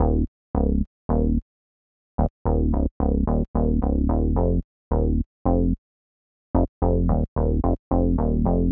03 Bass PT1.wav